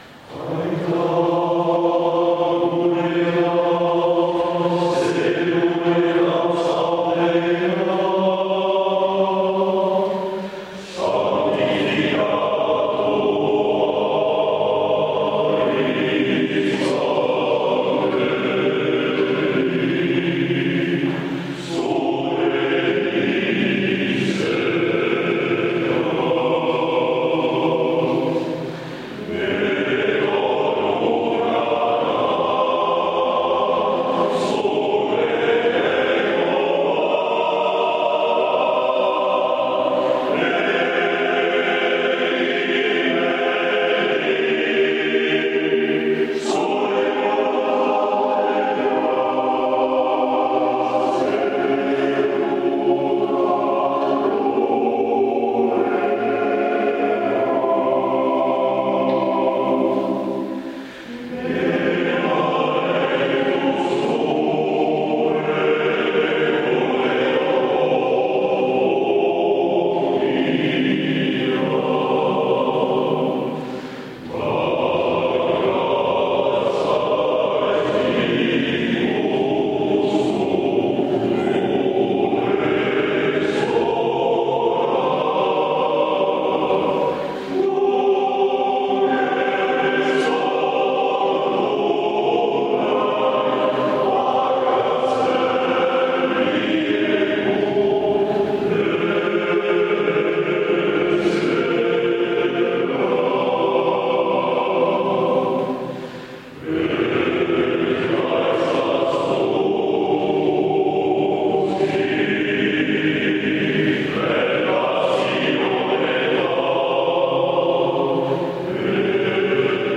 Extrait du concert